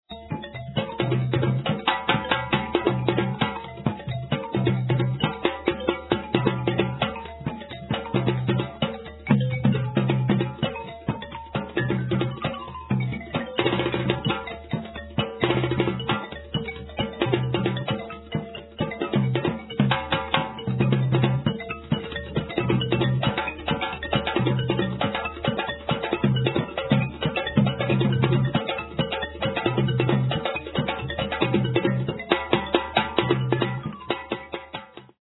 Mandinka Griot and balafon accompaniment.
A kora duet with the great Mandinka Griot